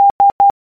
Letters S, s
S_morse_code.ogg.mp3